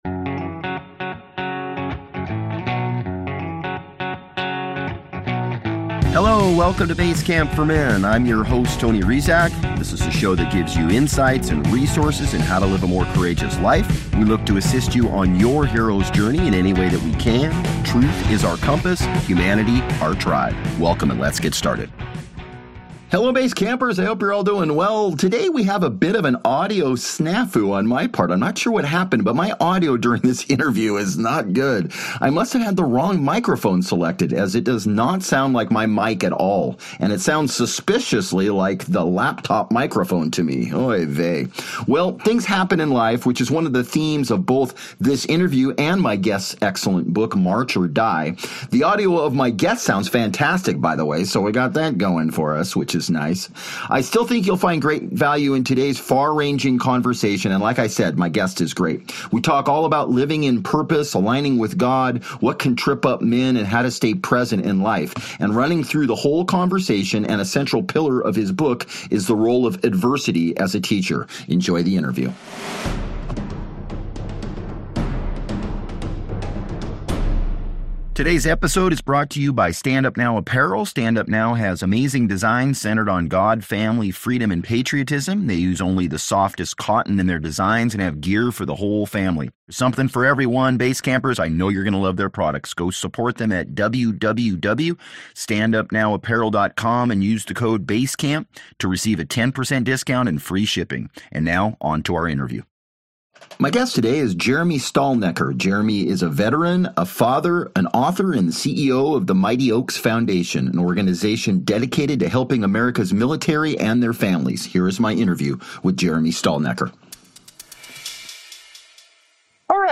Today we have a bit of an audio snafu on my part. I am not sure what happened but my audio during this interview is not good. I must have had the wrong microphone selected as it does not sound like my mike at all. It sounds suspiciously like the laptop microphone to me.